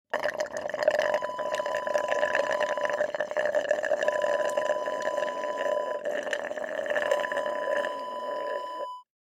Straw slurp sound effect .wav #6
Description: The sound of a straw slurping
Properties: 48.000 kHz 24-bit Stereo
A beep sound is embedded in the audio preview file but it is not present in the high resolution downloadable wav file.
Keywords: straw, slurp, slurping, ice, suck, sucking, empty, water, drink, soda, coke, juice, glass, paper cup
straw-slurp-preview-6.mp3